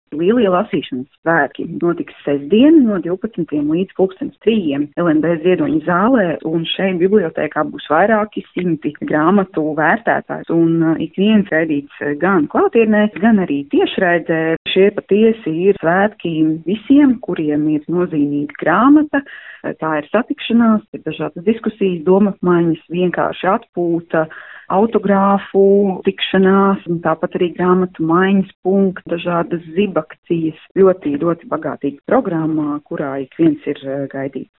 Kā intervijā Skonto mediju grupai (SMG) pastāstīja